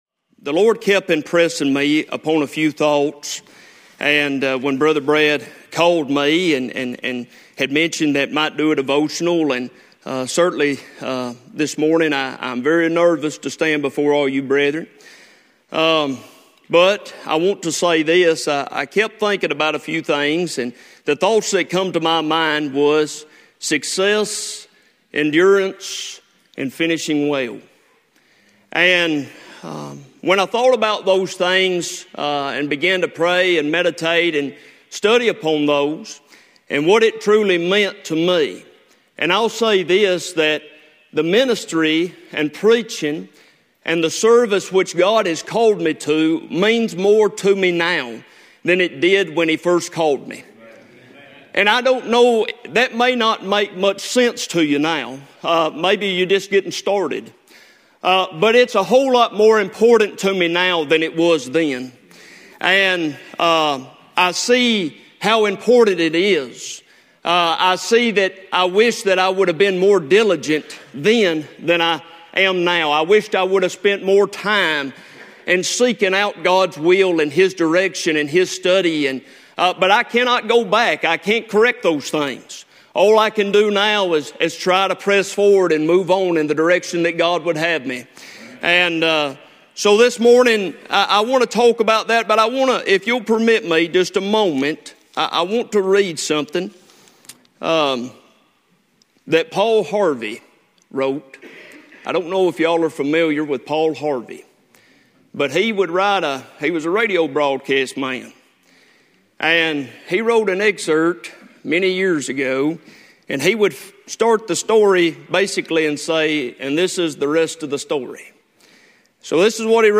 2025 Wednesday morning devotional from the 2025 session of the Old Union Ministers School.